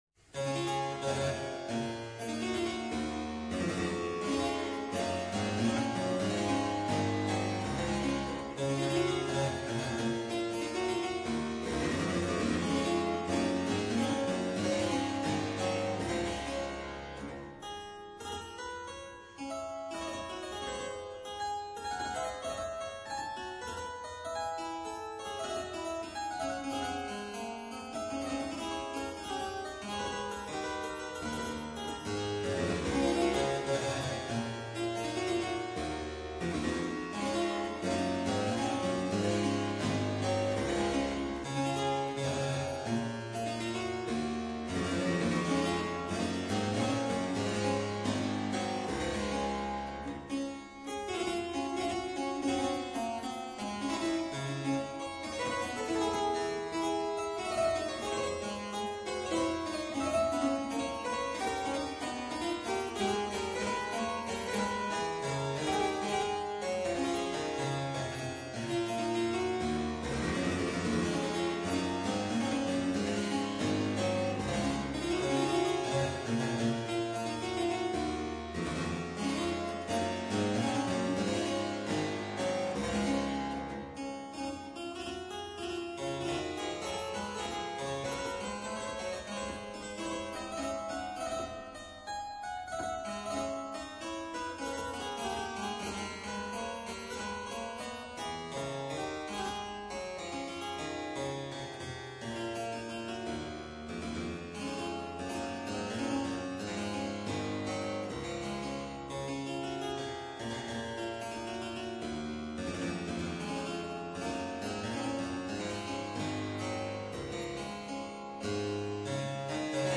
clavicembalo